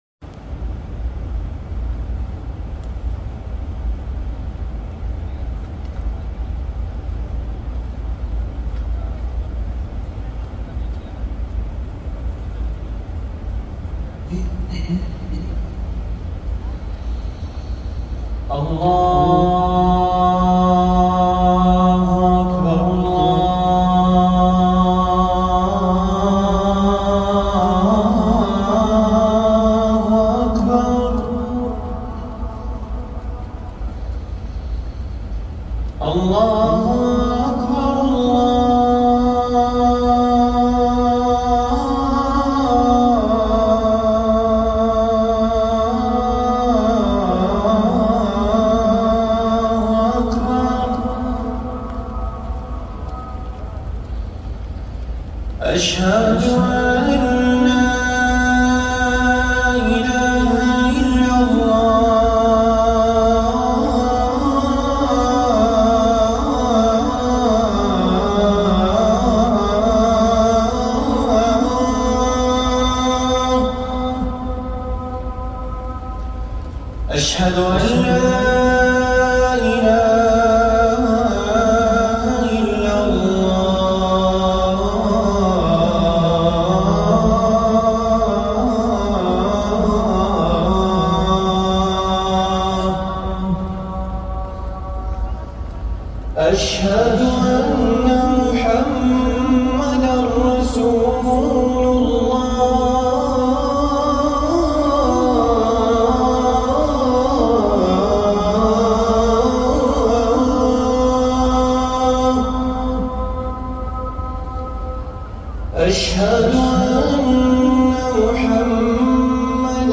الأذان الأول للفجر من الحرم النبوي > ركن الأذان